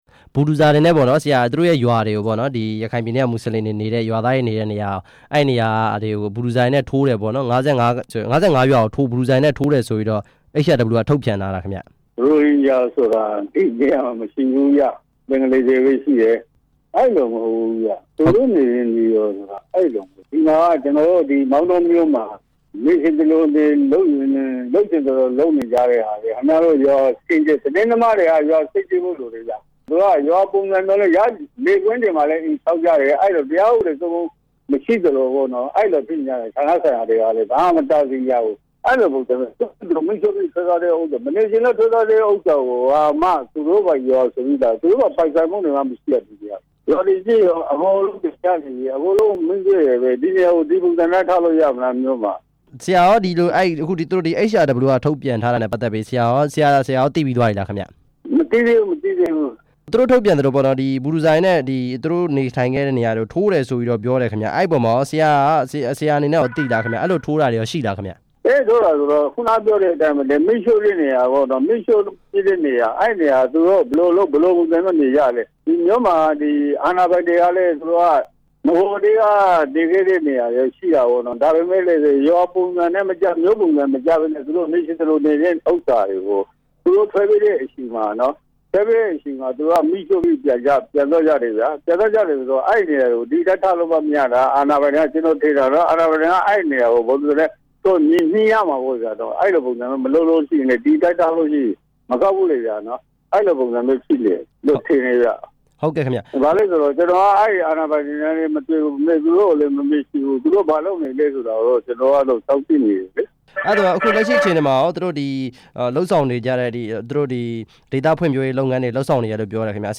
မောင်တောမြို့နယ် လွှတ်တော်ကိုယ်စားလှယ် ဦးမောင်အုန်းနဲ့ ဆက်သွယ်မေးမြန်းချက်